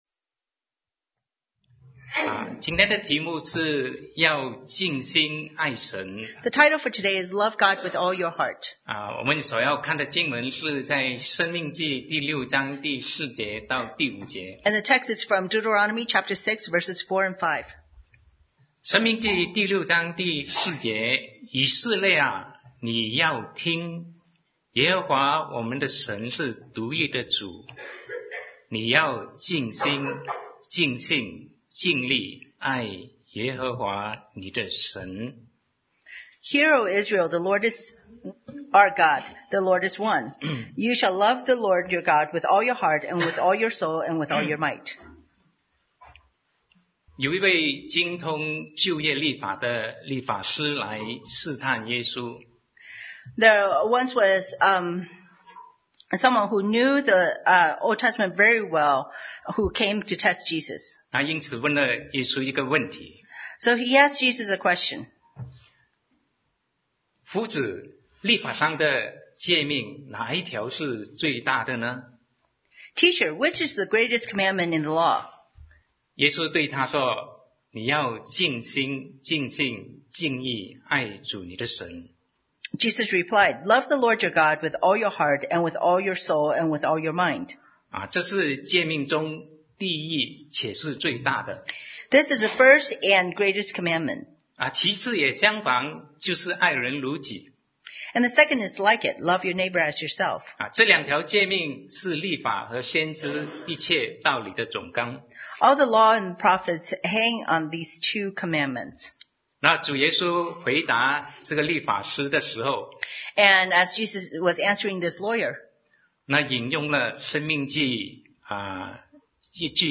Sermon 2019-03-03 Love God with All Your Heart